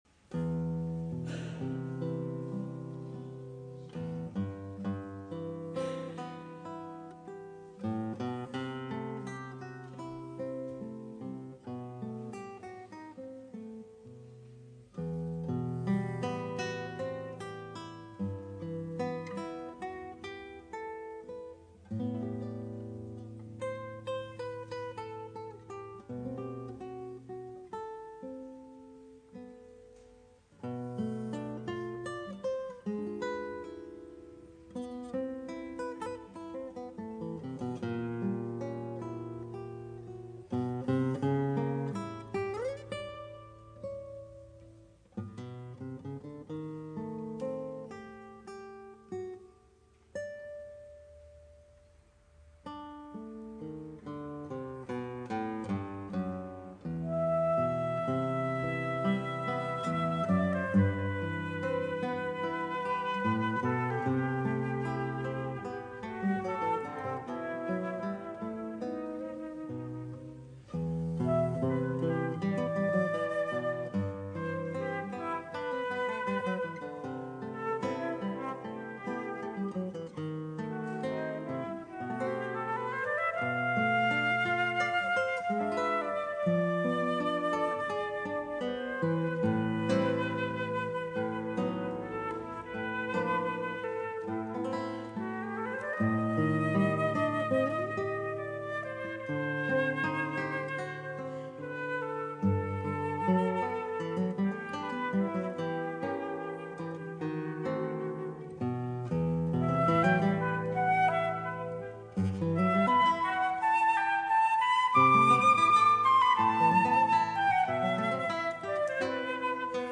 flauto
chitarra
concerto